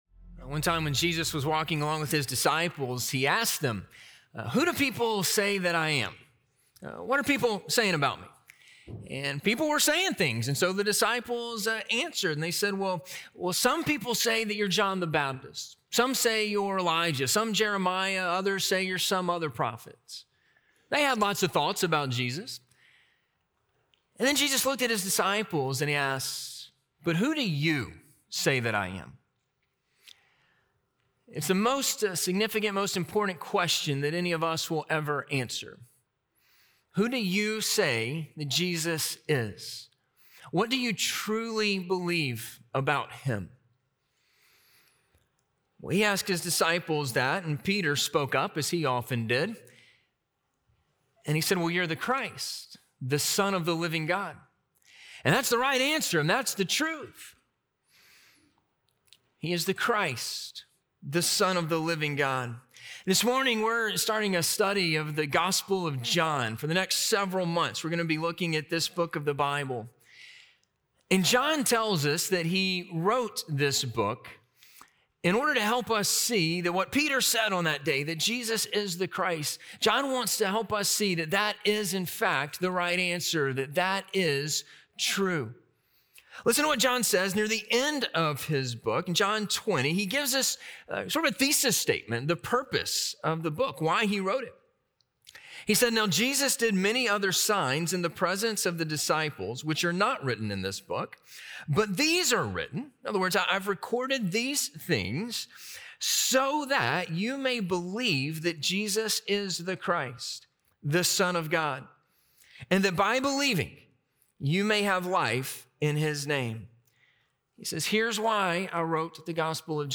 We Have Seen His Glory - Sermon - Ingleside Baptist Church